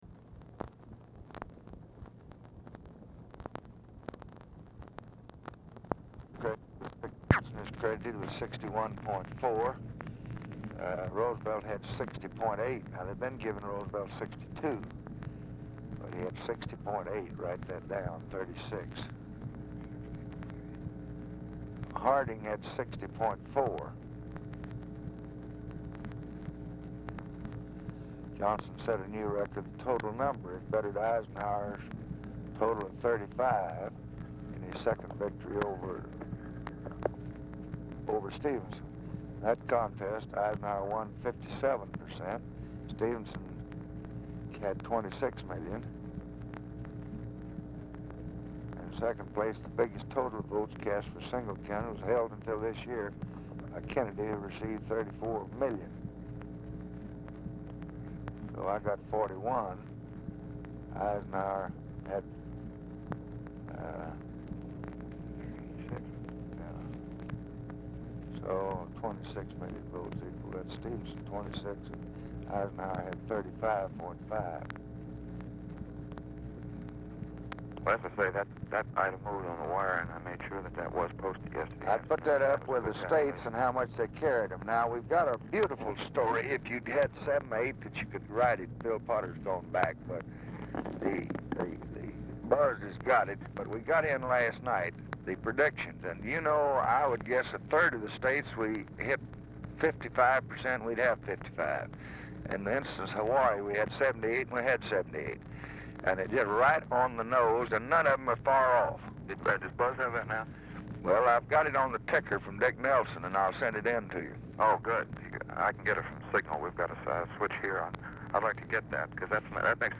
Telephone conversation # 6257, sound recording, LBJ and MAC KILDUFF, 11/6/1964, 10:24AM | Discover LBJ
Format Dictation belt
Location Of Speaker 1 LBJ Ranch, near Stonewall, Texas
Specific Item Type Telephone conversation